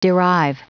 Prononciation du mot derive en anglais (fichier audio)
Prononciation du mot : derive